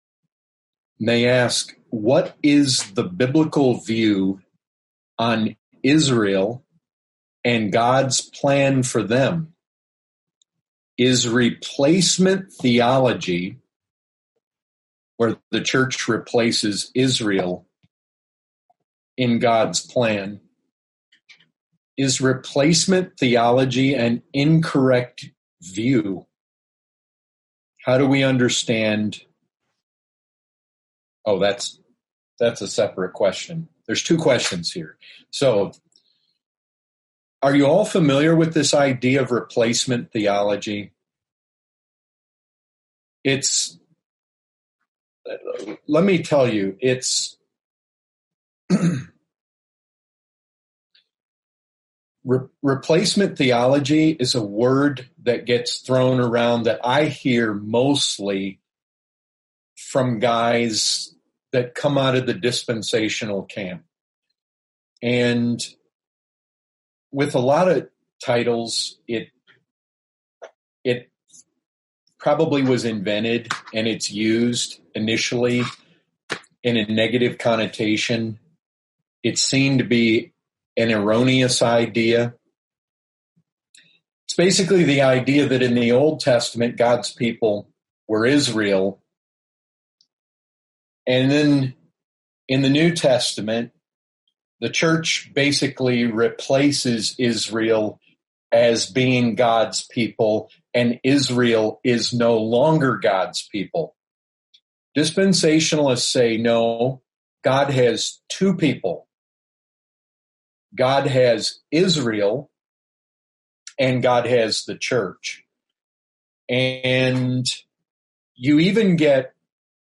2020 Category: Questions & Answers Topic